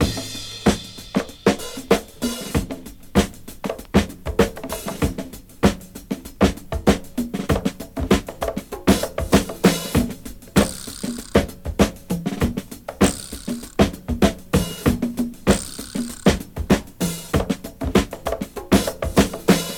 • 97 Bpm Fresh House Drum Loop E Key.wav
Free drum loop sample - kick tuned to the E note. Loudest frequency: 2158Hz
97-bpm-fresh-house-drum-loop-e-key-ZTk.wav